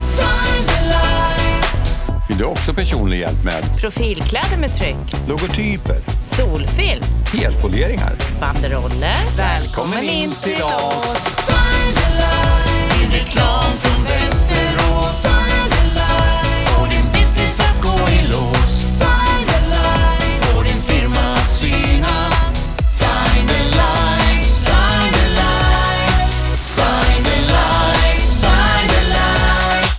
SPOTIFY Radioreklam